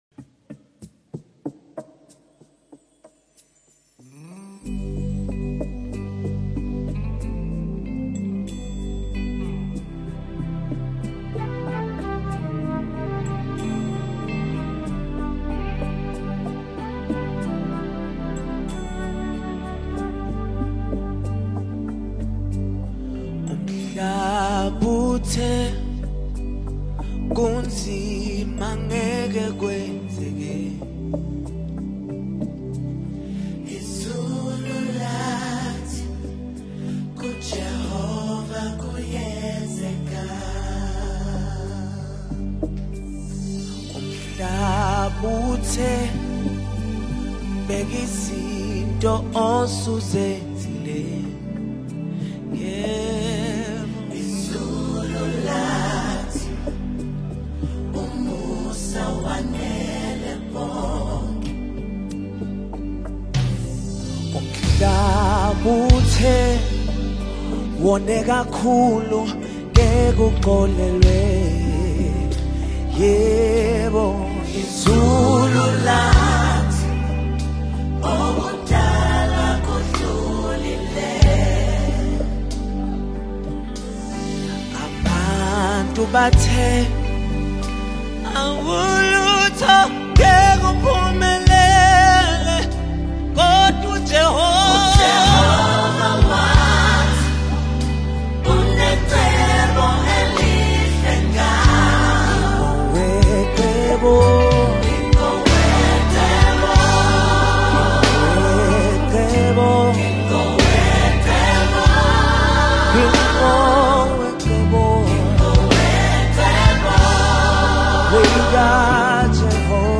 A heartfelt worship song with a strong message of faith
Anointed and powerful vocals
A live recording that enhances the worship experience